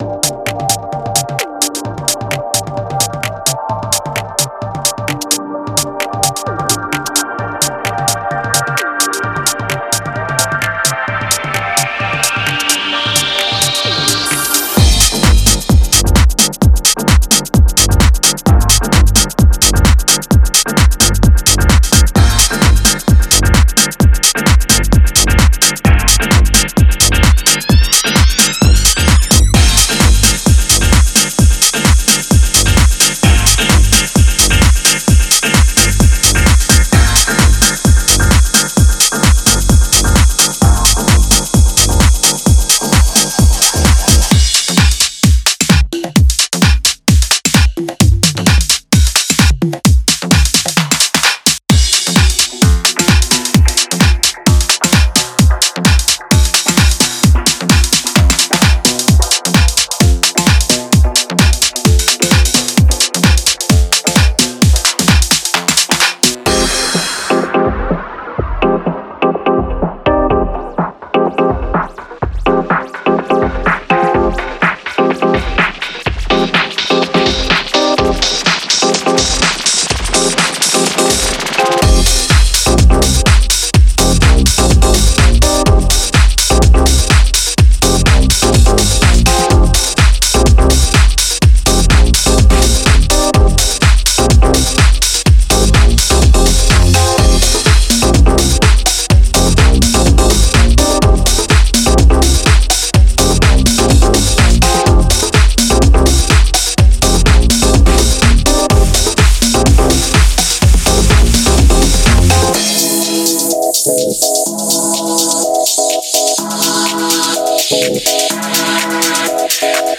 Genre:Tech House
デモサウンドはコチラ↓